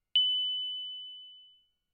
Roland Juno 6 Sine pluck " Roland Juno 6 Sine pluck F6 ( Sine pluck90127)
标签： F6 MIDI音符-90 罗兰朱诺-6 合成器 单票据 多重采样
声道立体声